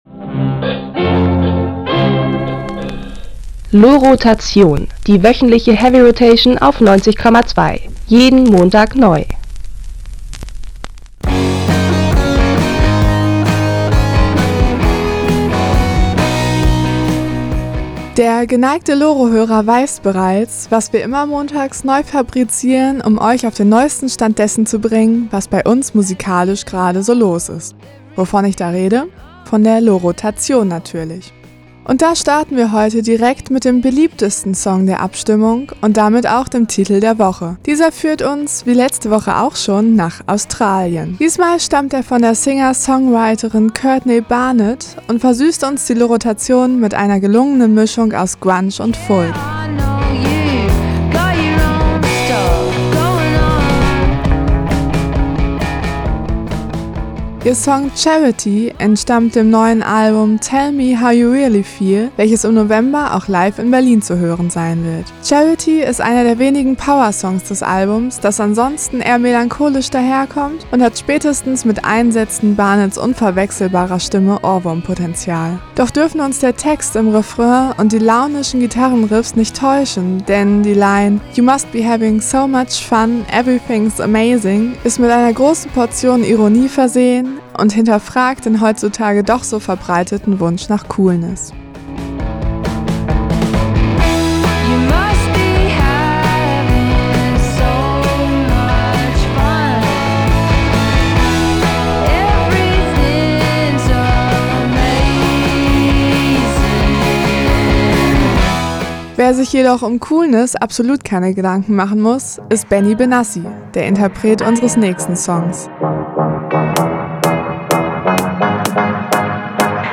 Unsere Musikredakteur*innen bereiten gerade begeistert unsere fünf Titel in einem Audiobeitrag auf.